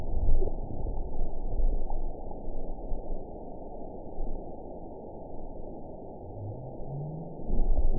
event 912220 date 03/21/22 time 13:47:33 GMT (3 years, 1 month ago) score 6.66 location TSS-AB03 detected by nrw target species NRW annotations +NRW Spectrogram: Frequency (kHz) vs. Time (s) audio not available .wav